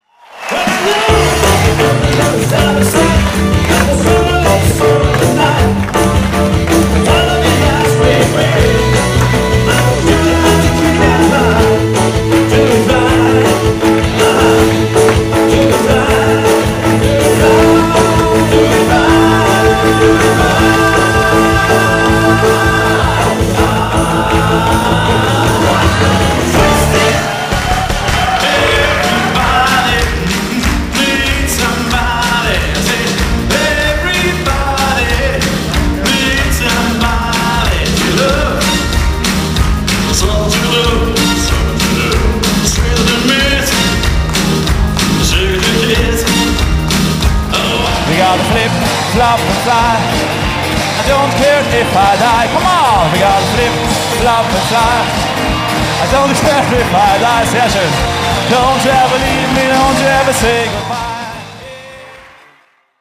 Live Short